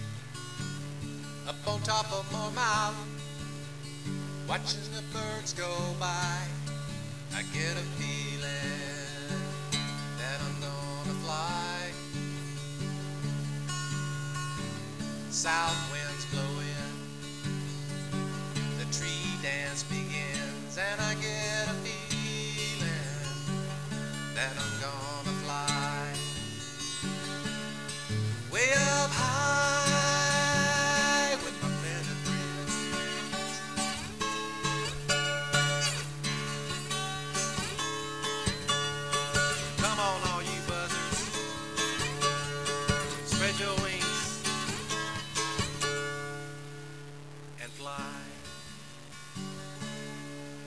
Buzzard song "live" G wav/1.06mb